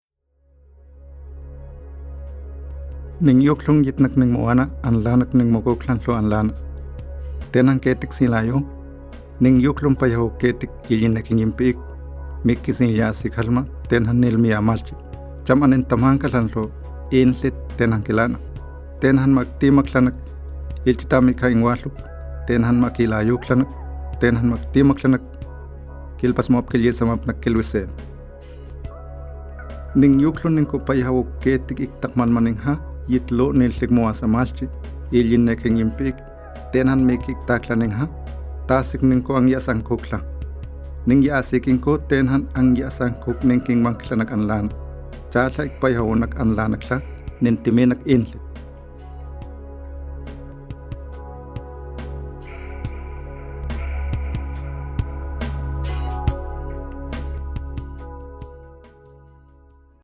Spots Radiales